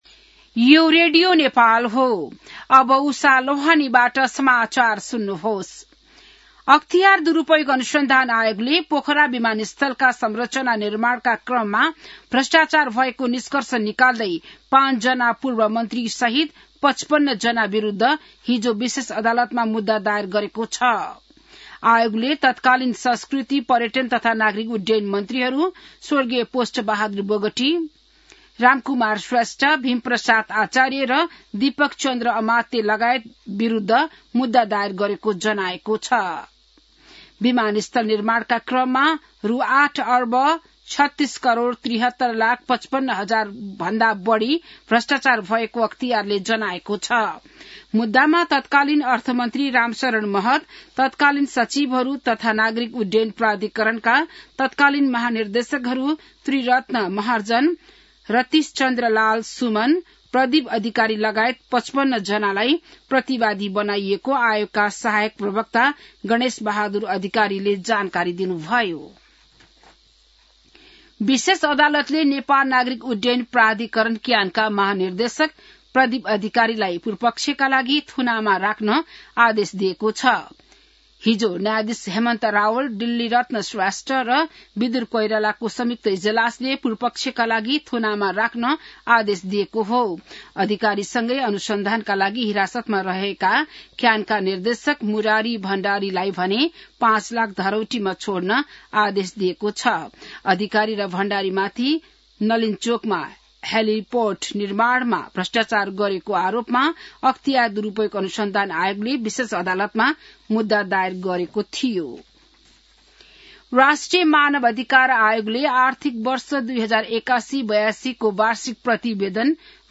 बिहान १० बजेको नेपाली समाचार : २२ मंसिर , २०८२